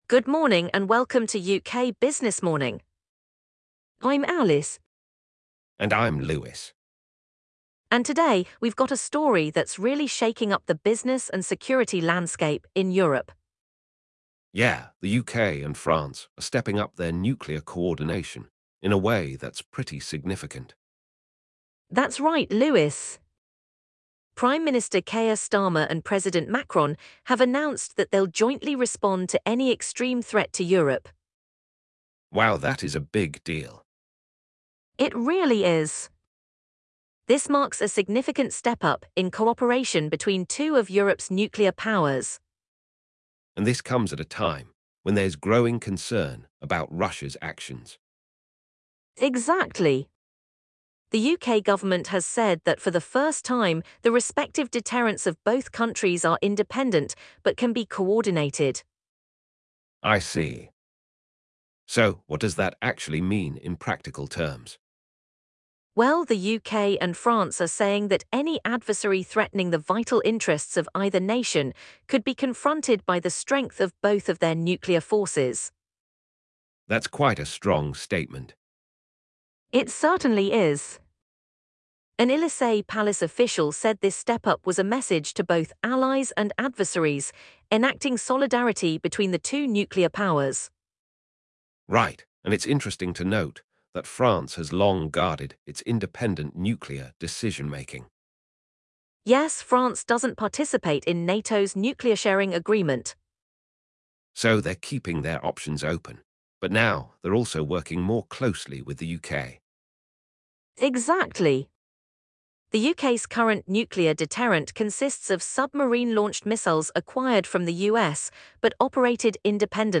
The hosts discuss the implications of this partnership, including potential operational changes like closer submarine patrols and the development of new long-range missiles.